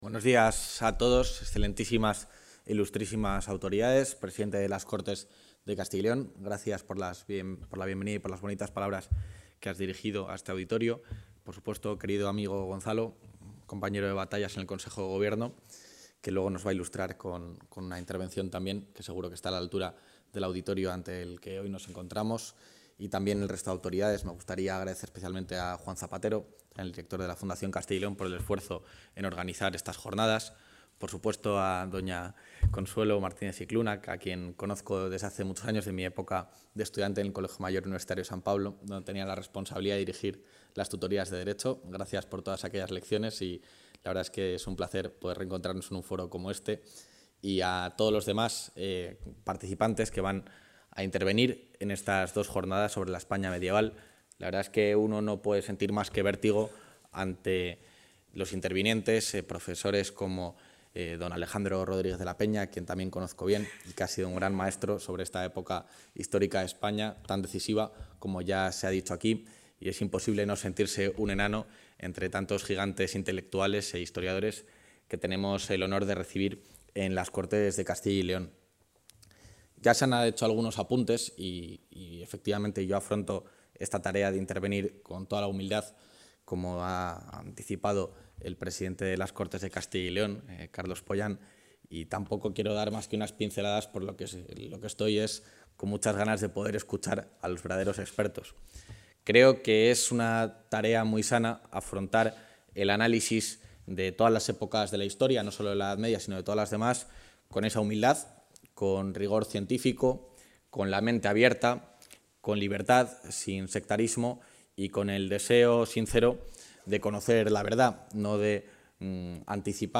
Intervención del vicepresidente de la Junta.
El vicepresidente de la Junta de Castilla y León, Juan García-Gallardo, ha inaugurado hoy en las Cortes de Castilla y León el Congreso 'La España medieval: un mundo de fronteras territoriales, religiosas y culturales'.